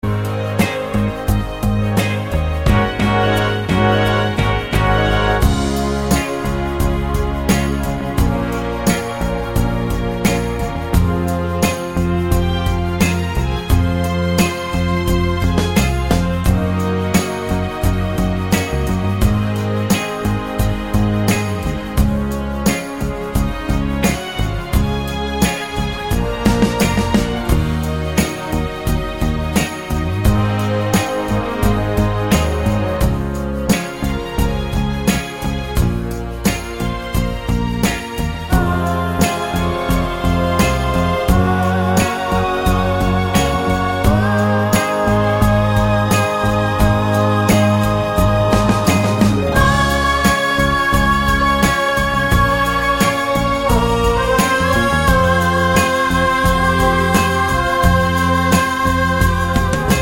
no Backing Vocals Crooners 3:23 Buy £1.50